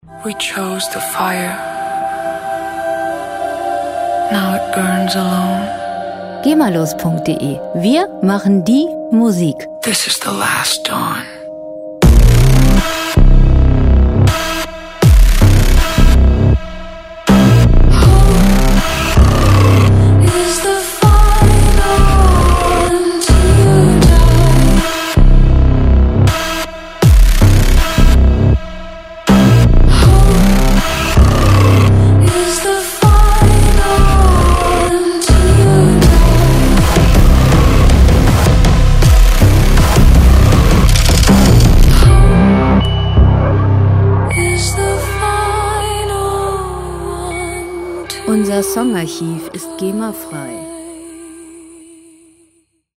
• Doomstep